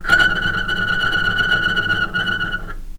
healing-soundscapes/Sound Banks/HSS_OP_Pack/Strings/cello/tremolo/vc_trm-F#6-pp.aif at b3491bb4d8ce6d21e289ff40adc3c6f654cc89a0
vc_trm-F#6-pp.aif